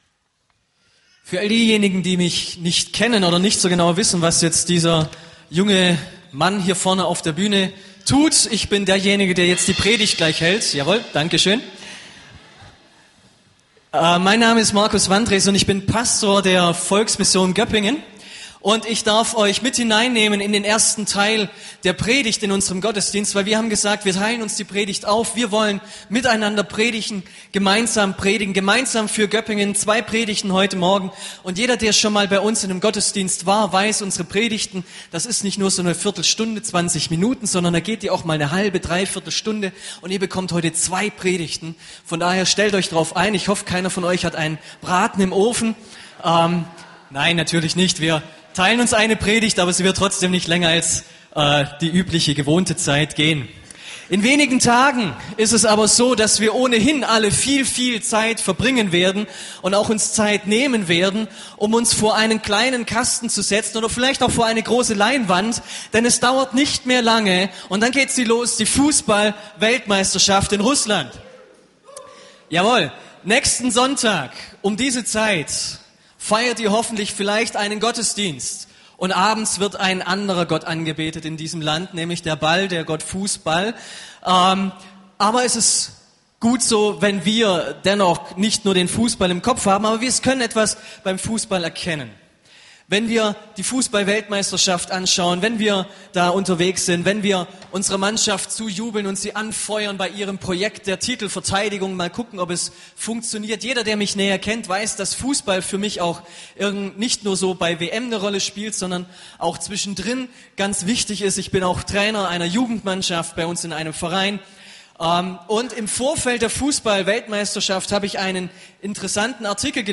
Gemeinsamer Gottesdienst in der Stadthalle Göppingen zusammen mit der Volksmission Göppingen